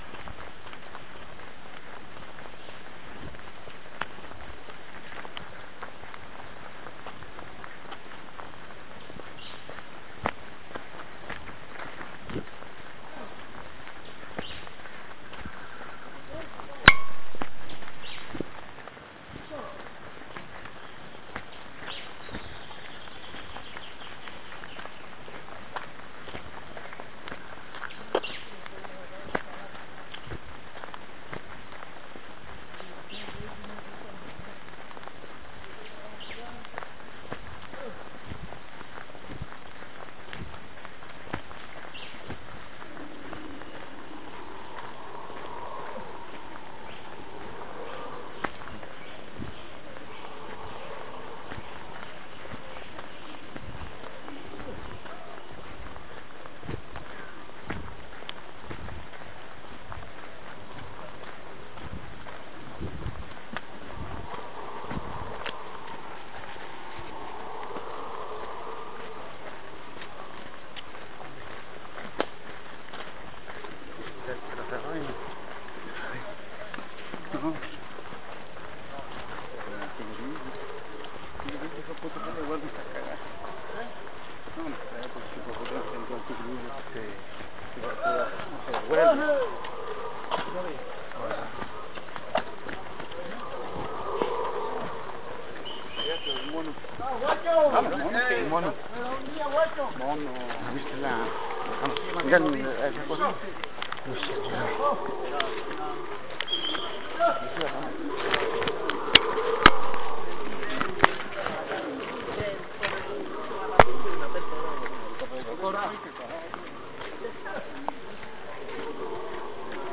Mono aullador
Lugar: Parque Nacional Natural Tayrona, Colombia.
Equipo: Grabadora periodista digital, modelo: VR5230